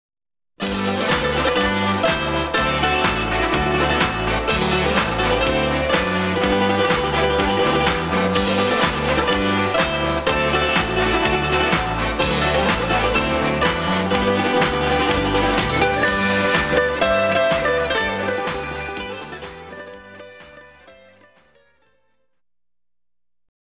10 authentic dances and melodies. Instrumental